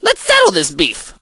crow_start_01.ogg